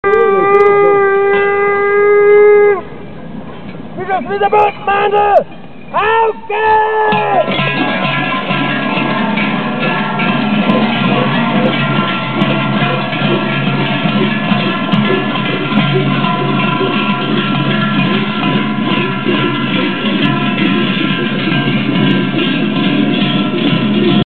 ... wenn sich die Dämmerung und der Nebel über das Bischofswieser Tal zieht und man aus der Ferne ein grauenhaftes Rütteln und Scheppern vernimmt, dann ist es der 5. und 6. Dezemberabend an dem die Bischofswieser Buttnmandl ihr Unwesen treiben. Schaurige Gestalten, in Stroh gehüllt und mit Felllarven, mit schweren Kuhglocken versehen und langen Ruten in der Hand ziehen sie von Haus zu Haus.